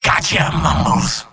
Robot-filtered lines from MvM.
Engineer_mvm_dominationpyro04.mp3